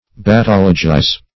Search Result for " battologize" : The Collaborative International Dictionary of English v.0.48: Battologize \Bat*tol"o*gize\ (b[a^]t*t[o^]l"l[-o]*j[imac]z), v. t. To keep repeating needlessly; to iterate.